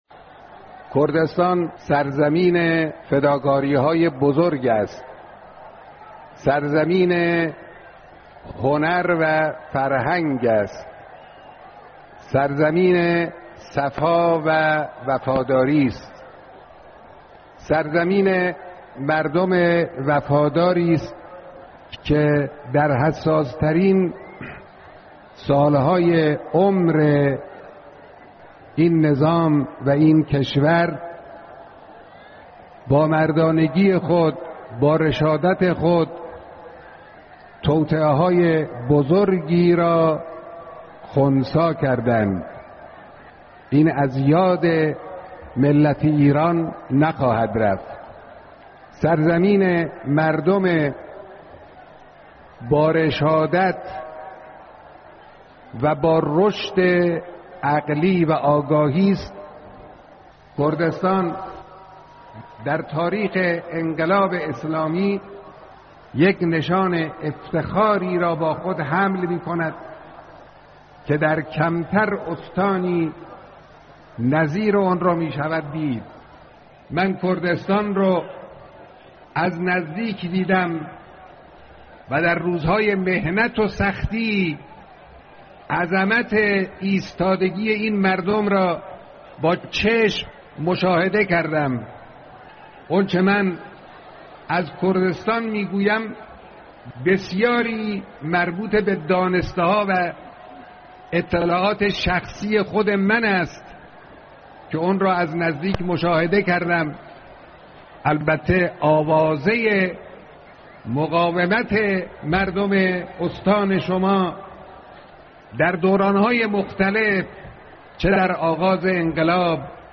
گزیده بیانات رهبر انقلاب در دیدار عمومی/کردستان، سرزمین فداکاری های بزرگ